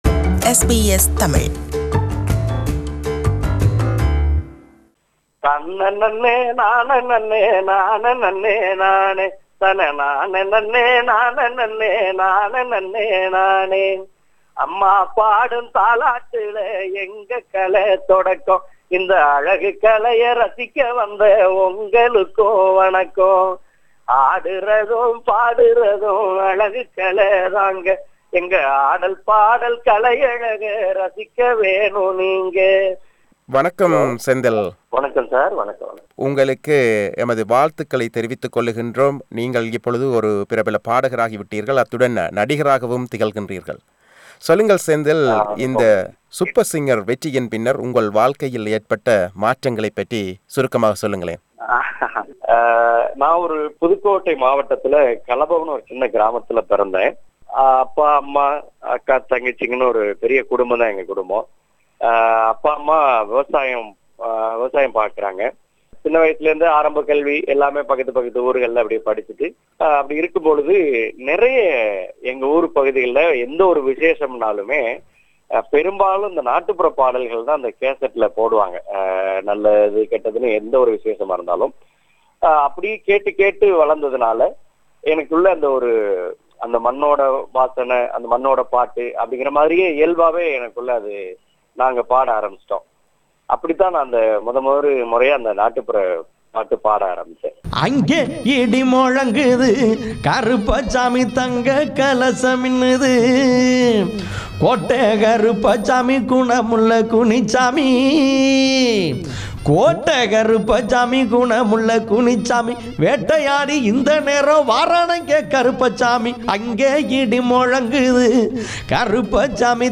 Interview with Folk Singer couple